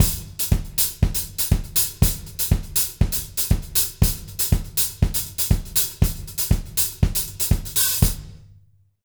120ZOUK 03-L.wav